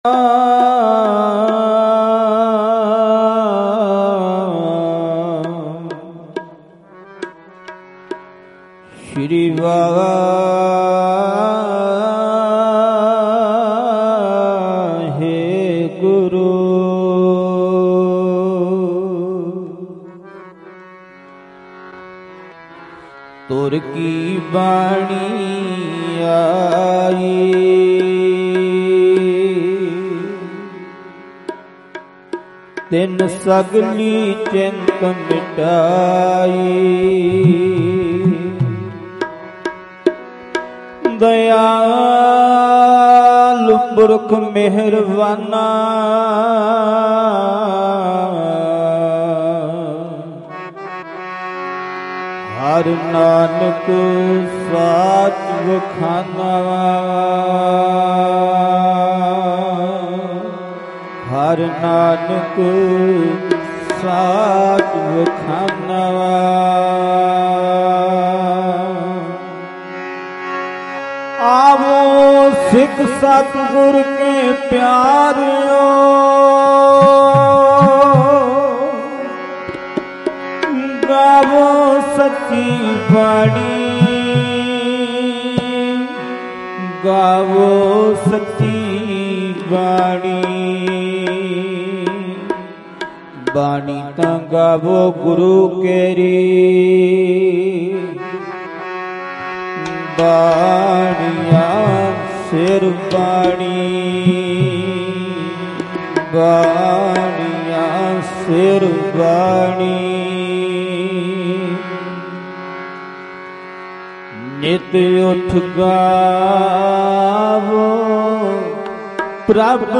Mp3 Poem Audio